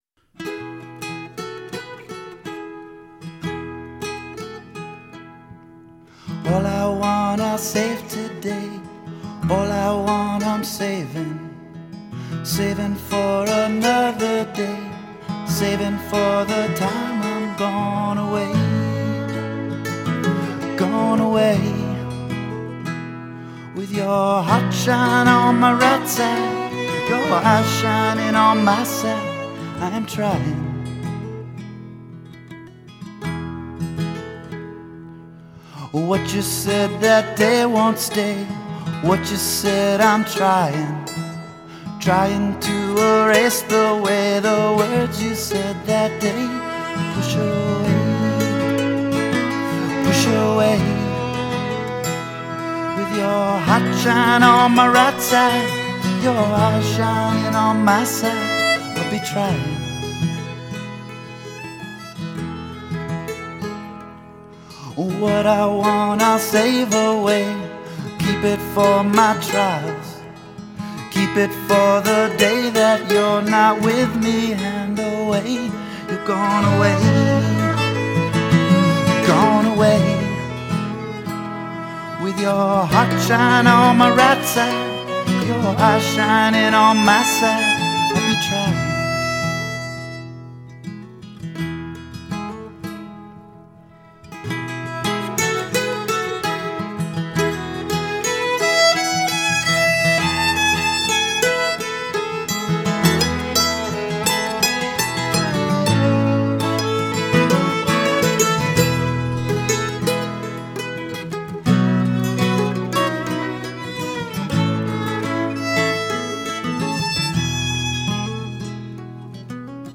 Guitar and vocals
Mandolin and fiddle